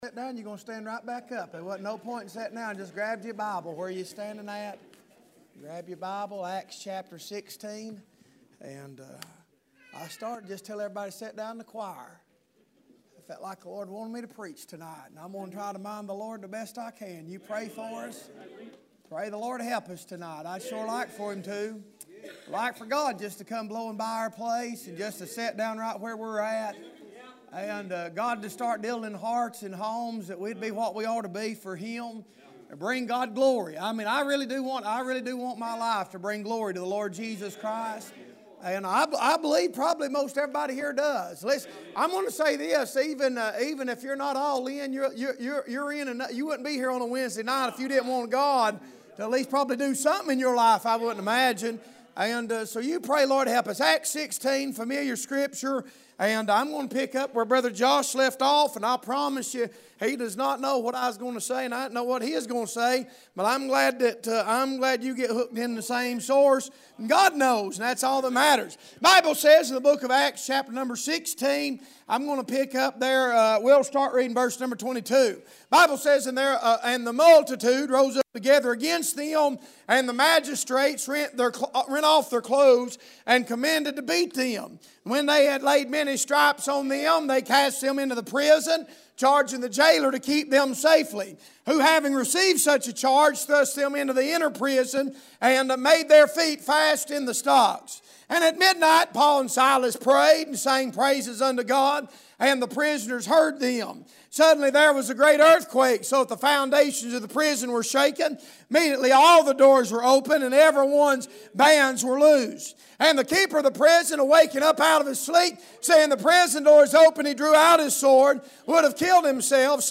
Passage: Acts 16: 22-35 Service Type: Wednesday Evening